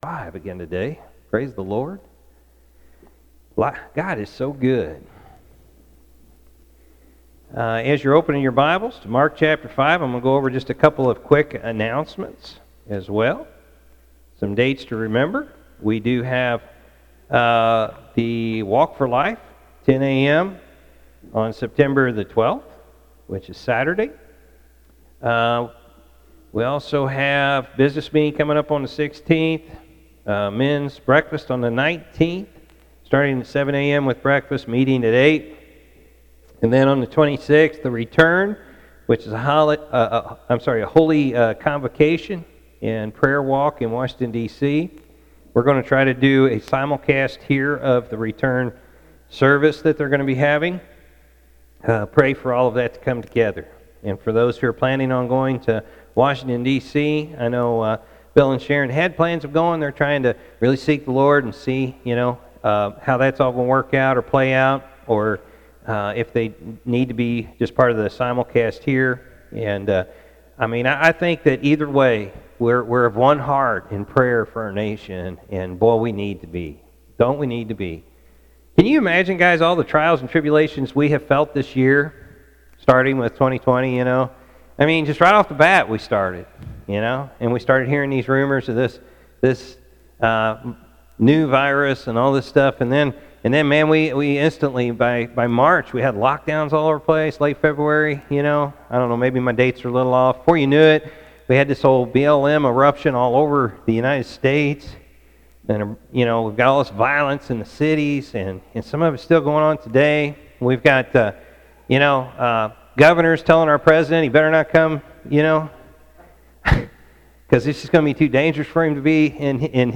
morning-service-sep.-6-2020.mp3